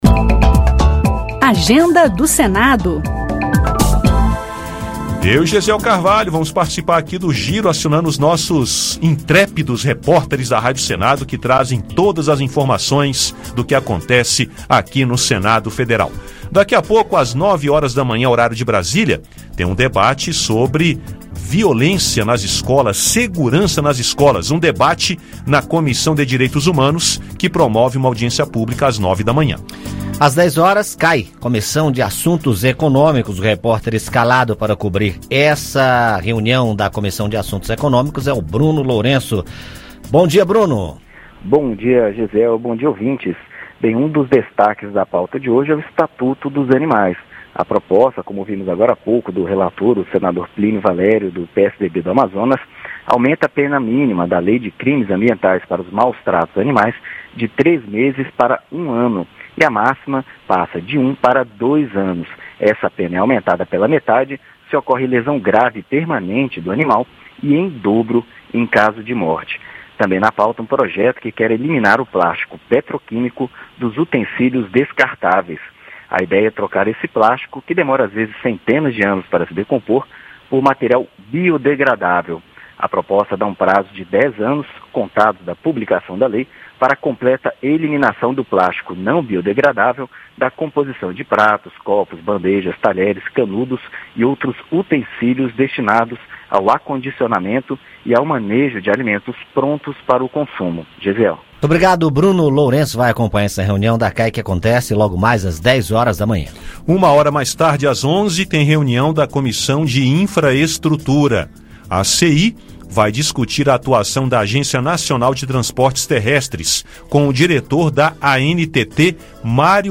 Ouça a agenda do Senado com a equipe de reportagem da Rádio Senado.